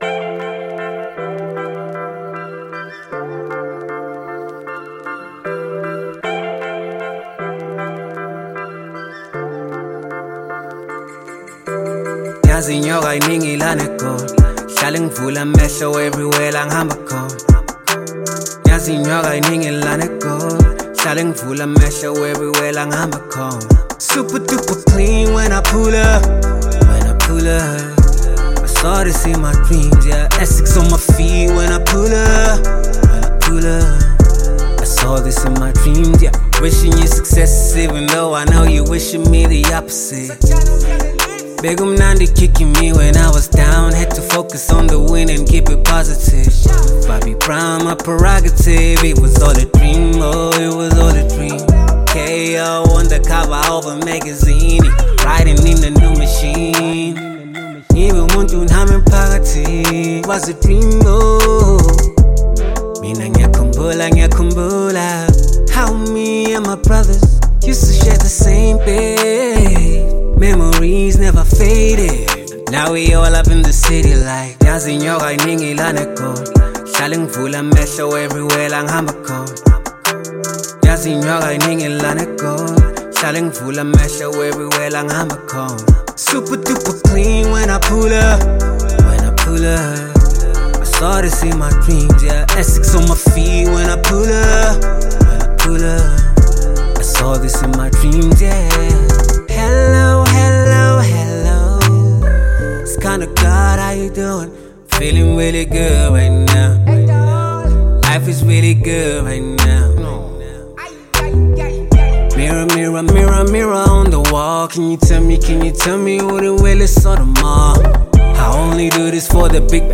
a perfect blend of rap and amapiano vibes
With his smooth flow, catchy hooks